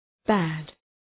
Προφορά
{bæd}